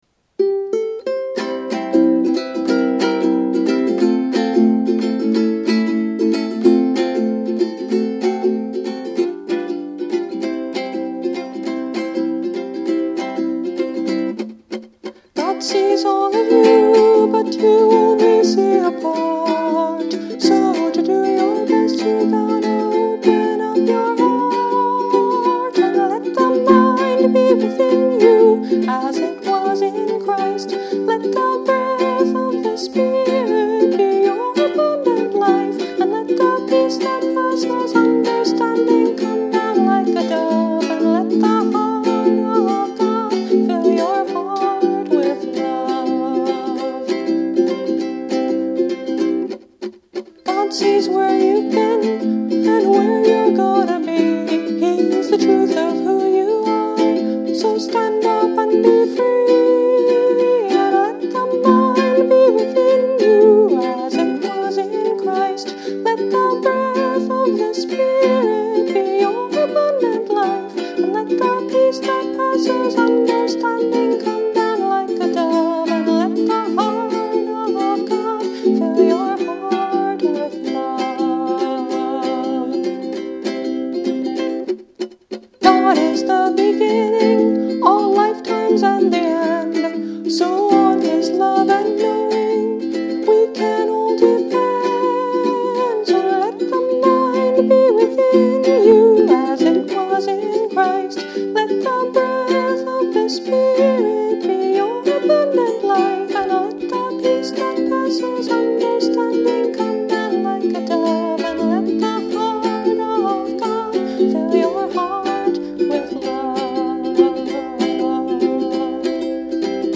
Instrument: Eventide – Mahogany Concert Ukulele